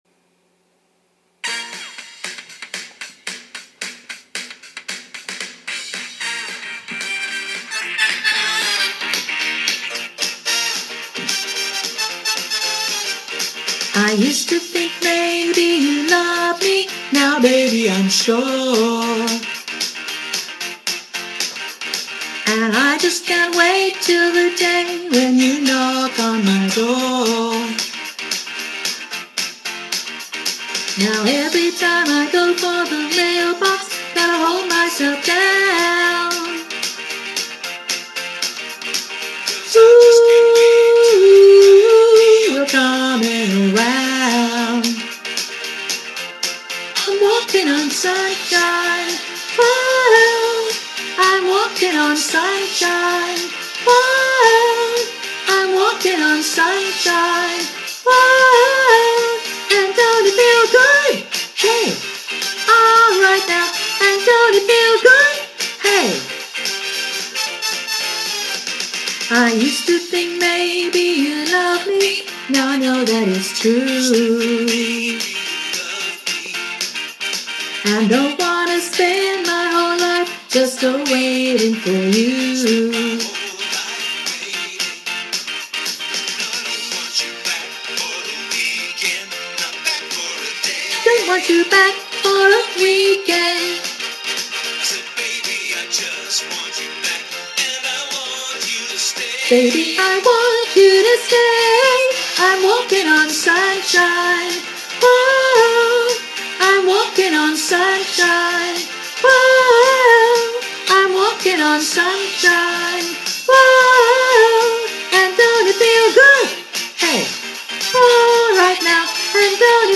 Vox Populi Choir is a community choir based in Carlton and open to all comers.